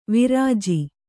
♪ virāji